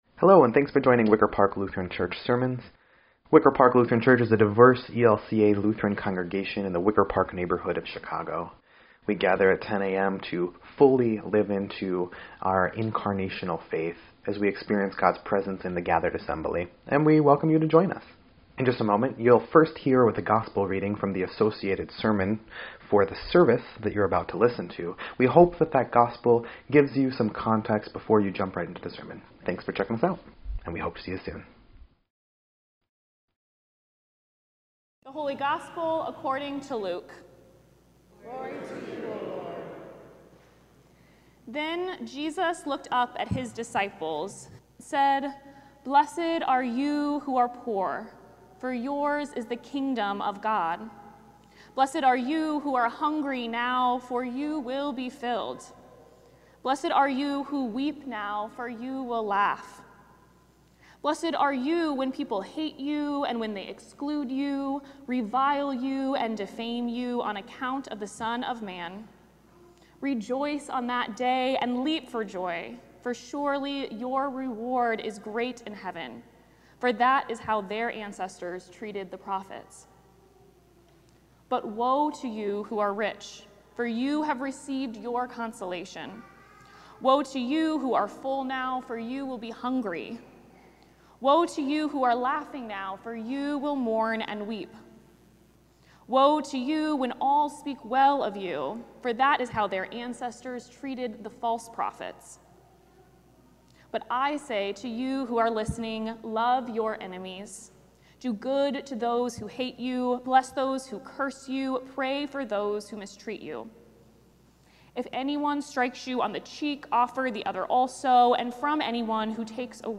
11.2.25-Sermon_EDIT.mp3